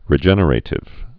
(rĭ-jĕnə-rātĭv, -ər-ə-tĭv)